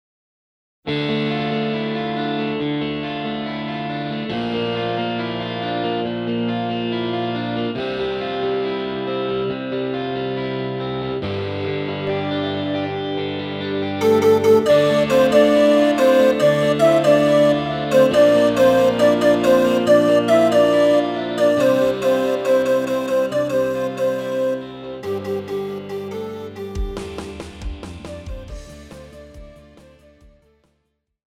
Žánr: Rock
BPM: 138
Key: G
MP3 ukázka s ML